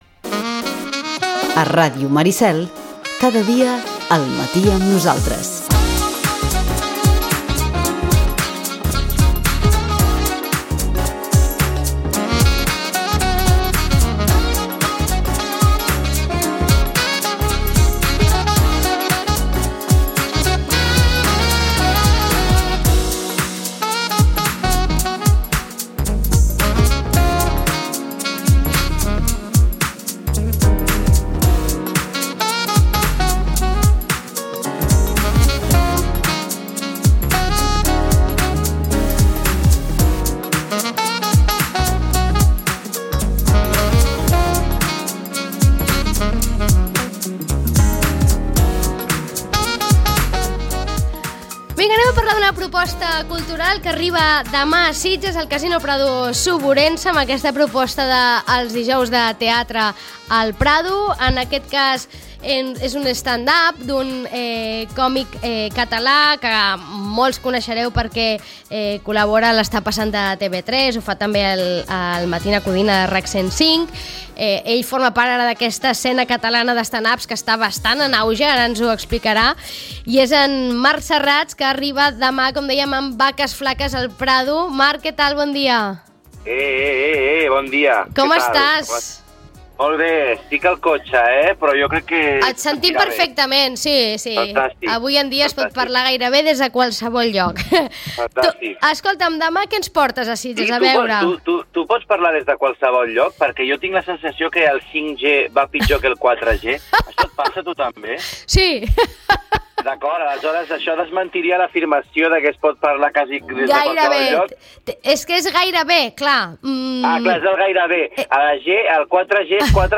N'hem parlat amb aquest humorista que demana a Sitges que ompli la sala.